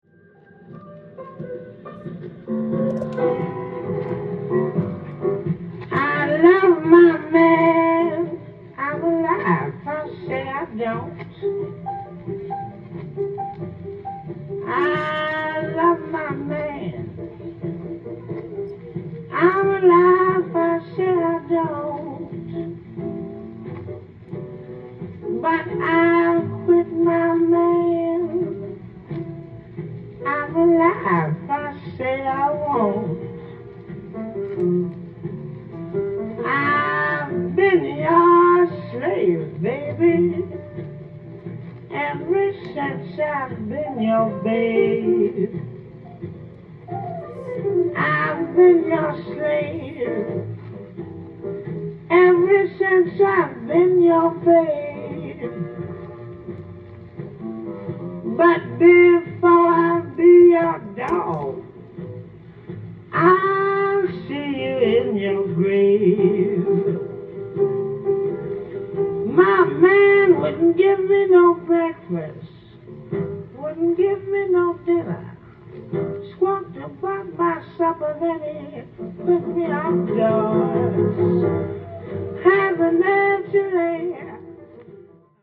店頭で録音した音源の為、多少の外部音や音質の悪さはございますが、サンプルとしてご視聴ください。
51年にボストンのSotyville Clubで行われたライヴの模様を収録。